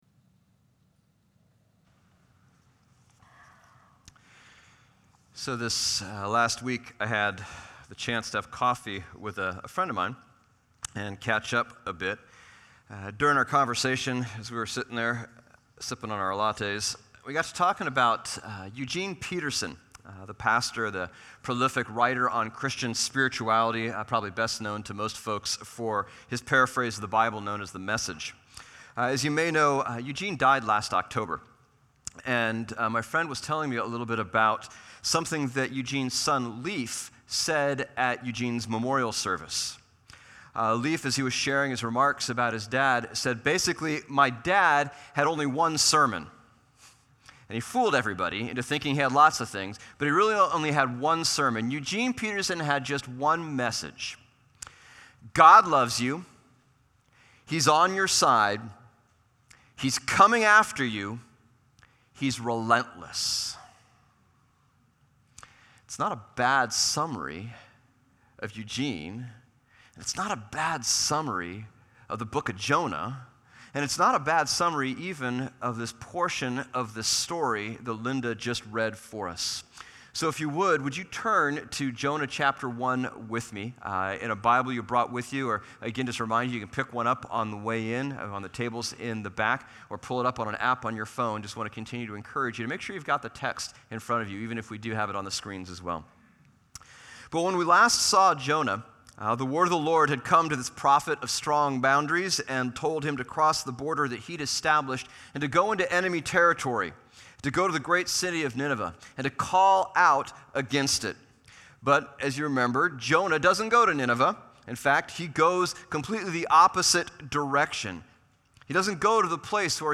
Lenten Sermon Series on Jonah